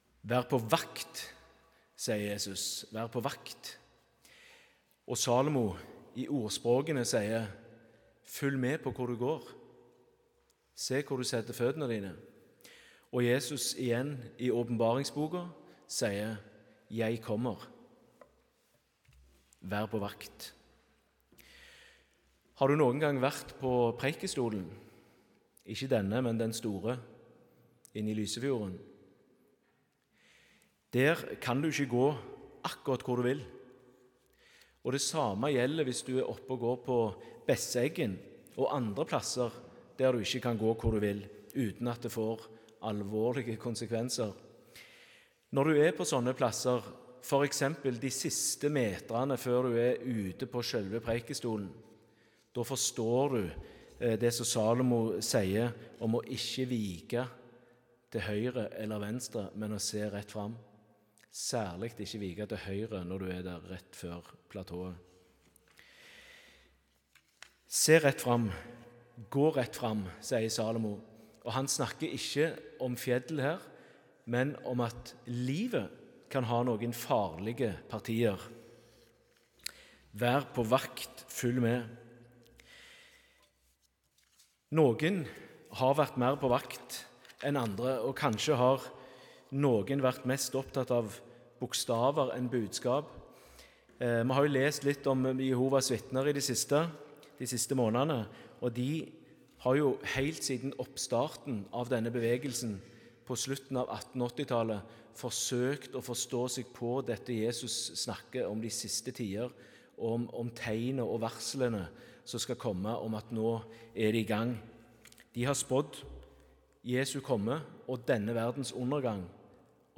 Tekstene Evangelietekst: Mark 13,21–27 Lesetekst 1: Ordsp 4,18–27 Lesetekst 2: Åp 22,12–17 Utdrag fra talen (Hør hele talen HER ) Tekstene « Vær på vakt!